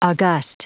Prononciation du mot august en anglais (fichier audio)
Prononciation du mot : august